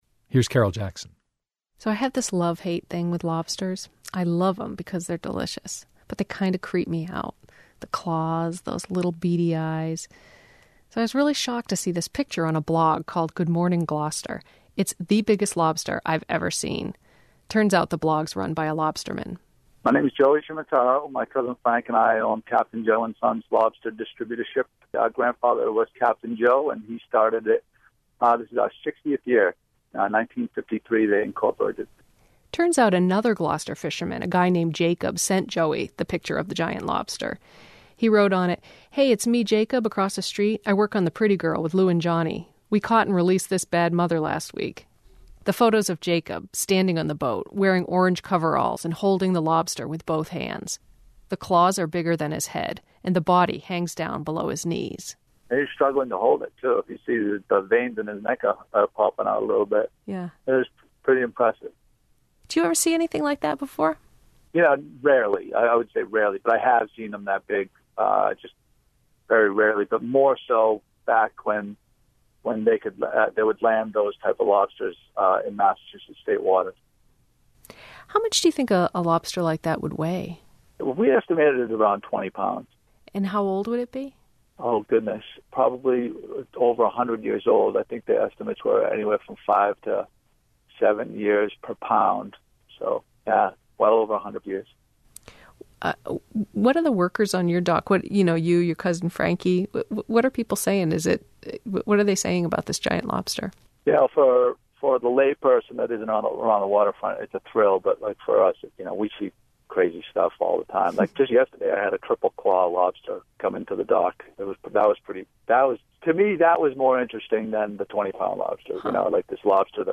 Audio from this story on American Public Radio